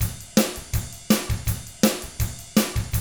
164ROCK T8-R.wav